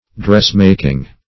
\Dress"mak`ing\
dressmaking.mp3